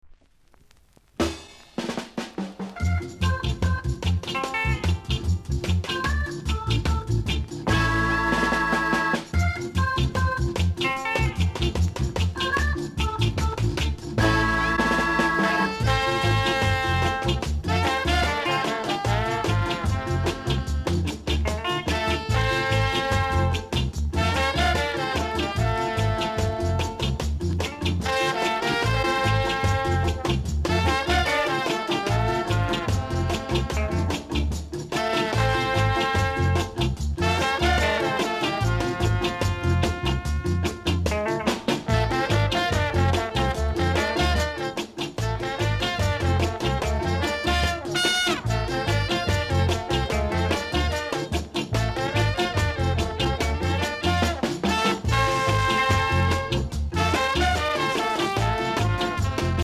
※小さなチリノイズが少しあります。
コメント NICE INST & BIG TUNE!!※レーベルのクレジット違います。